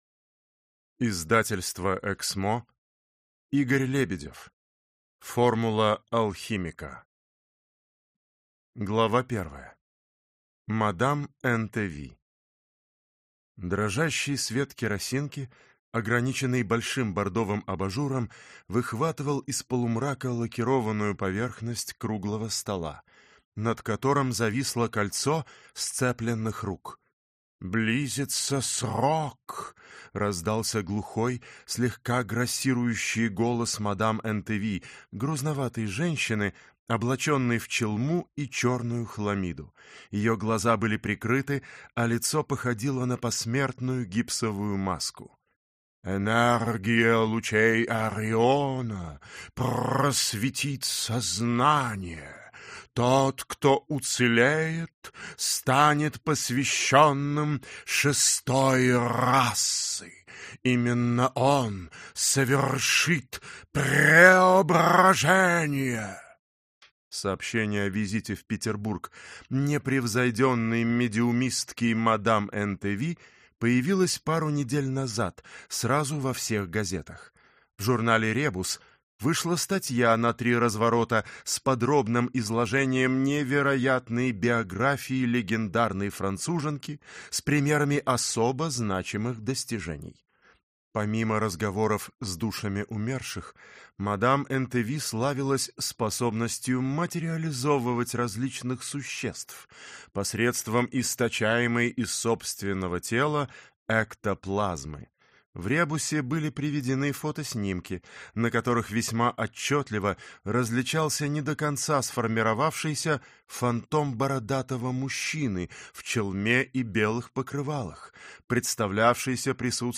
Аудиокнига Формула алхимика | Библиотека аудиокниг
Прослушать и бесплатно скачать фрагмент аудиокниги